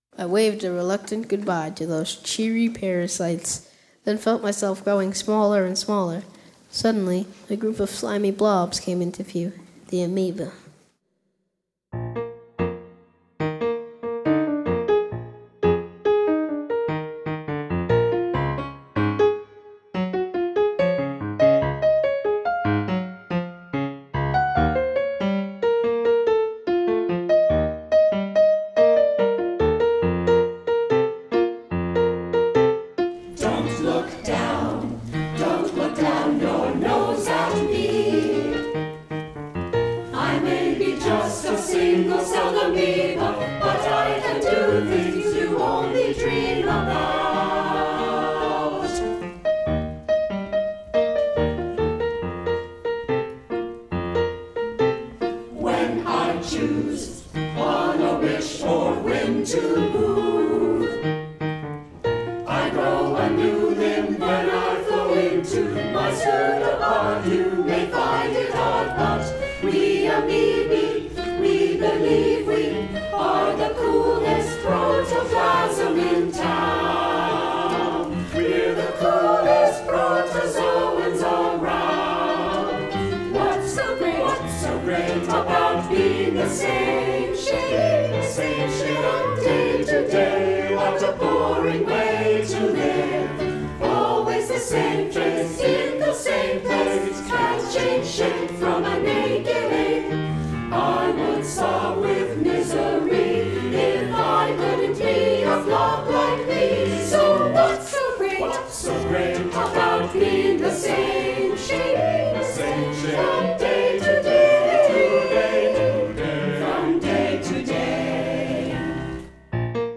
piano
Below are summaries and recordings of the songs of Powers of Ten as performed by the 2014 NCFO Festival Chorus.
• Amoeba – With a spiky, angular melody, the amoebae argue their case: "I would sob with misery if I couldn’t be a blob like me, so what’s so great about being the same shape from day to day?"